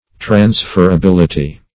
transferability - definition of transferability - synonyms, pronunciation, spelling from Free Dictionary
Transferability \Trans*fer`a*bil"i*ty\, n.